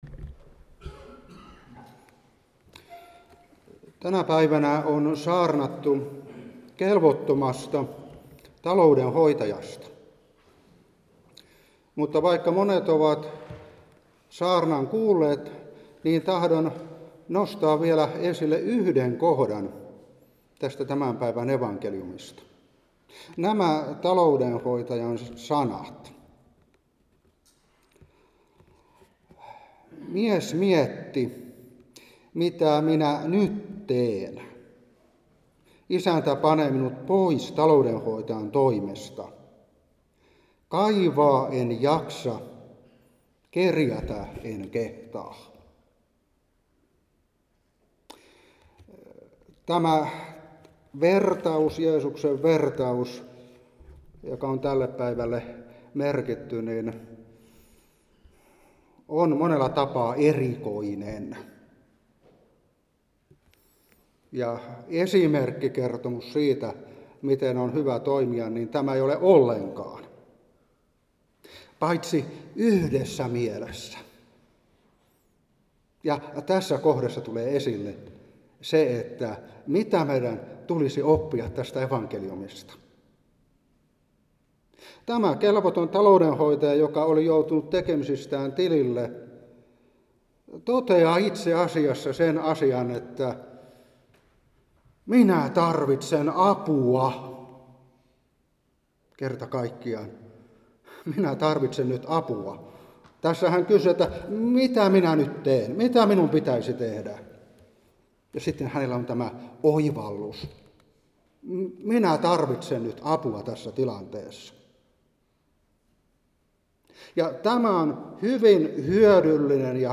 Seurapuhe 2025-8.